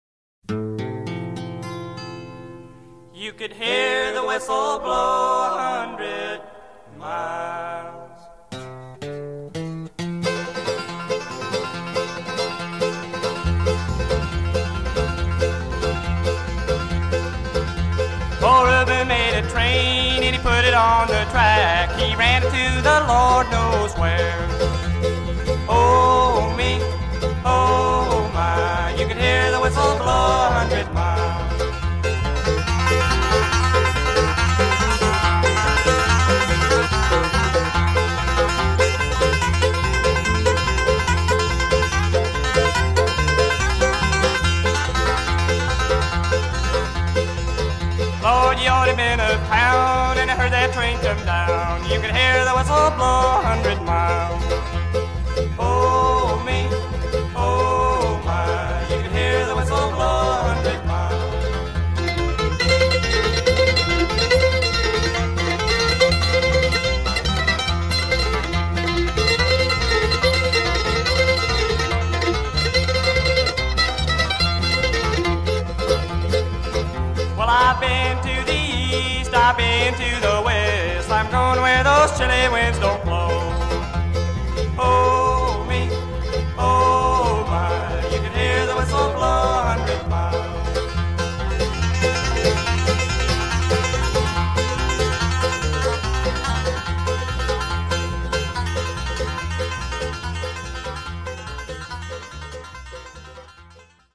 Traditional
This song is in the key of D.